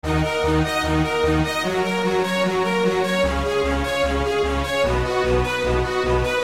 描述：管弦乐组合（弦乐、黑暗的皮兹、铜管等......）。
Tag: 75 bpm Hip Hop Loops Strings Loops 1.08 MB wav Key : Unknown